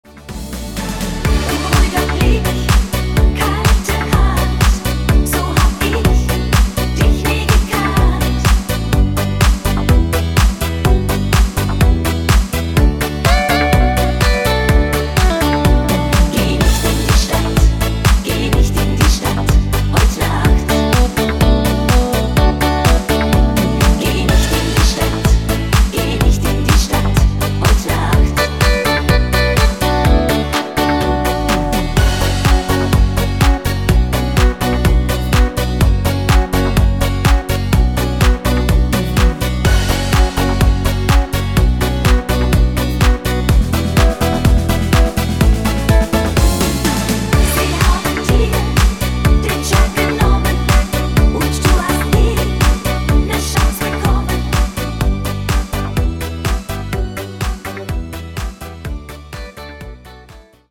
(Männerstimmen geeignet)
Rhythmus  Discofox
Art  ML Remix, Schlager 2020er, Weibliche Interpreten